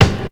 Kick_15.wav